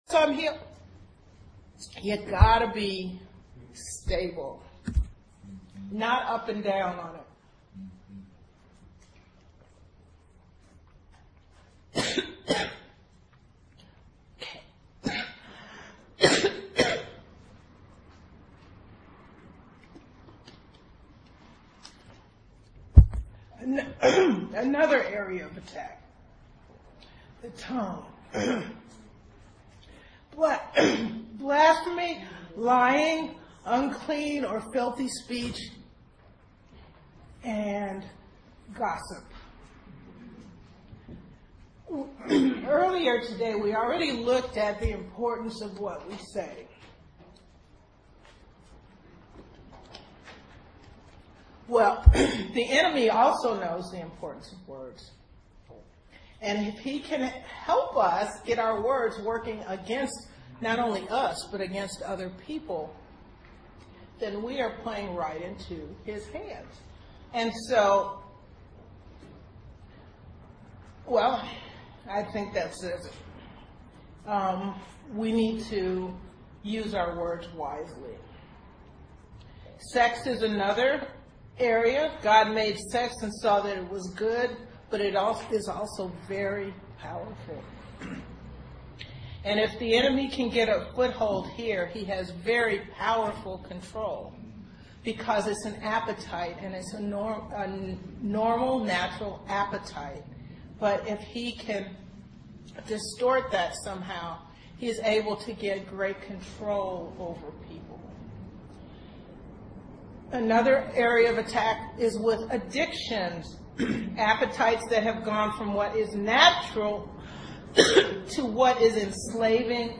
Healing & Deliverance Seminar Part 3b